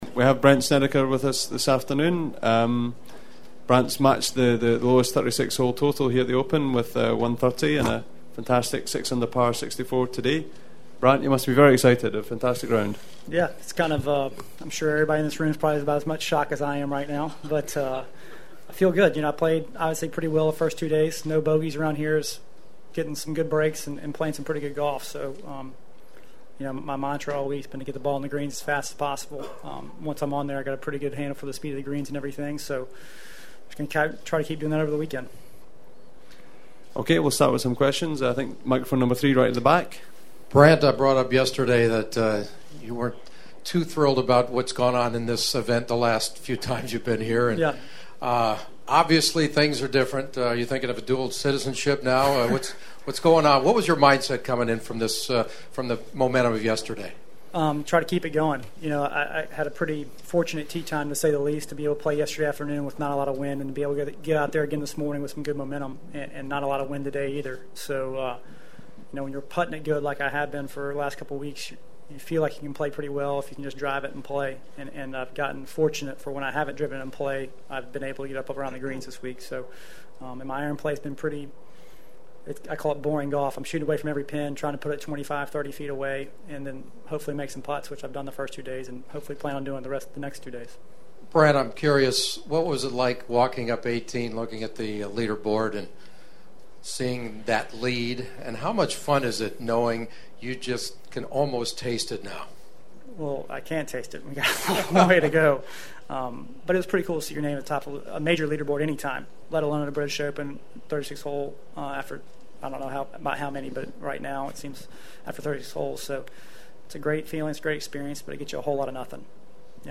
As for Sneds…he’s as good a guy as you’ll meet in sports just a regular Joe (or Brandt) who I’ve occassionally had the pleasure to connect with as in last year at The Open Championship where he was the midway leader at Royal Lytham and St. Anne’s and he talked about that experience with me afterwards…